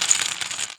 UI_Reset.wav